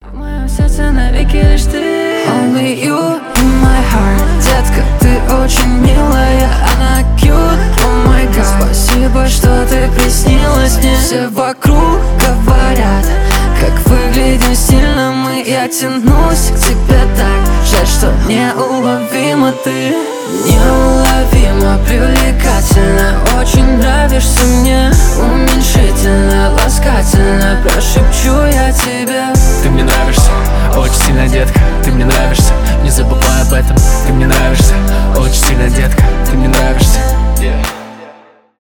rnb
поп
дуэт